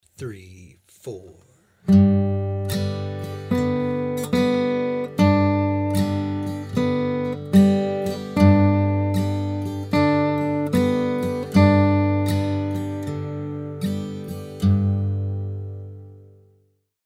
Listen to an example of improvising using only the chord tones of the G chord